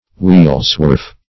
Wheelswarf \Wheel"swarf`\, n.